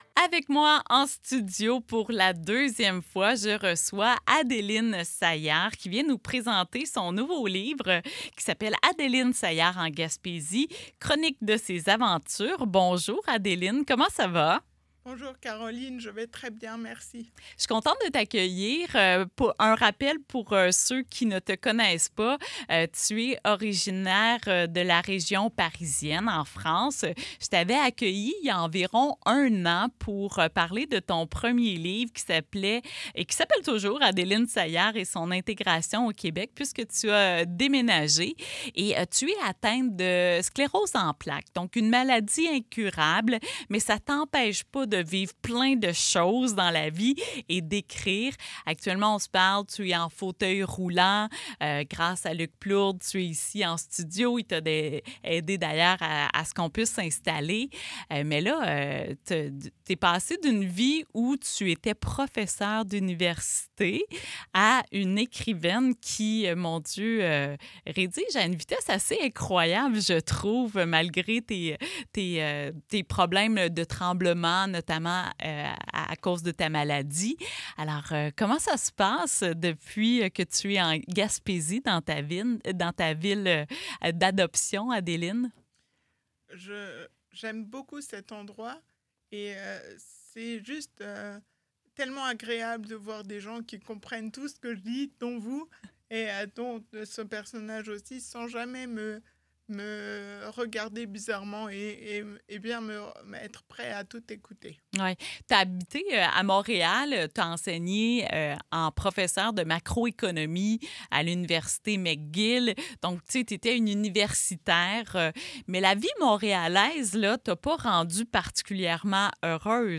l’a reçue en studio.